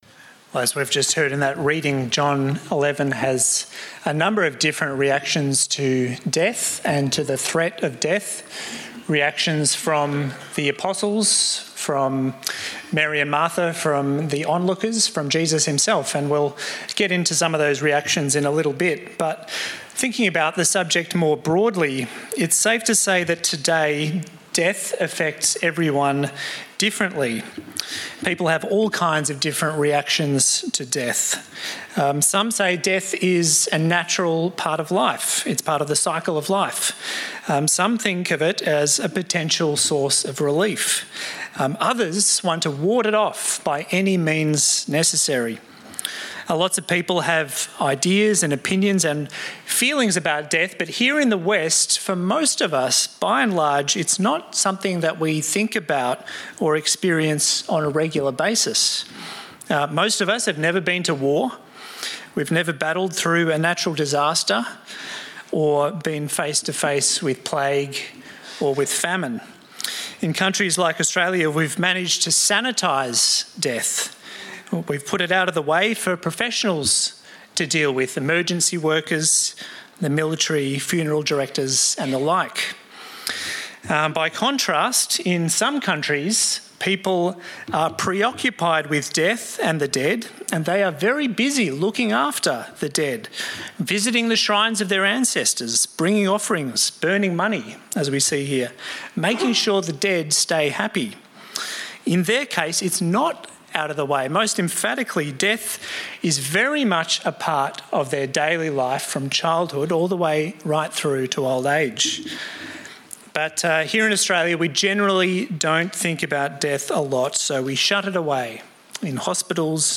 Sermons | St Alfred's Anglican Church